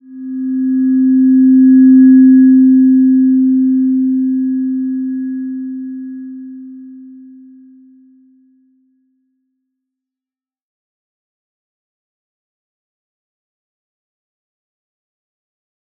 Slow-Distant-Chime-C4-mf.wav